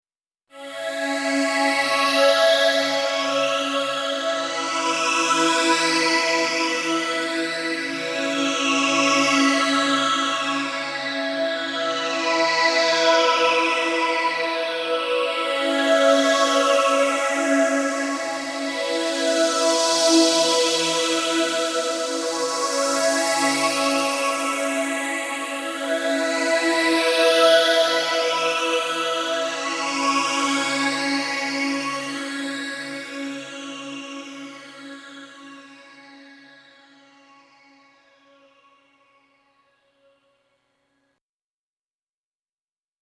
JH_Vsynth_Verse.mp3